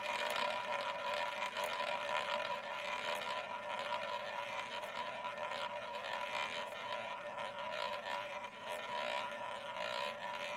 spin.mp3